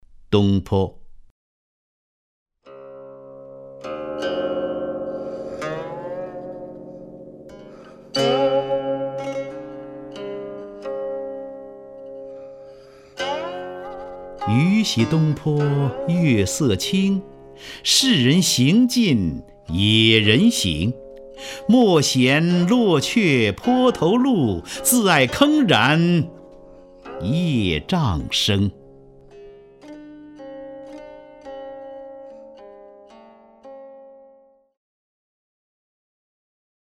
张家声朗诵：《东坡》(（北宋）苏轼) （北宋）苏轼 名家朗诵欣赏张家声 语文PLUS